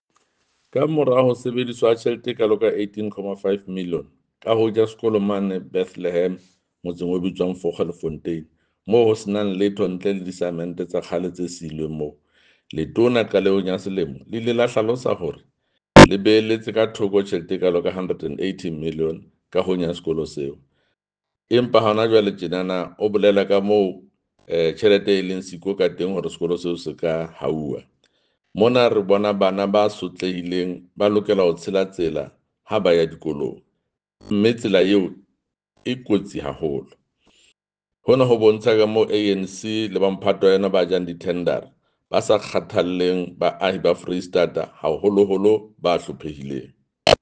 Sesotho soundbite by Jafta Mokoena MPL with images here, here, here and here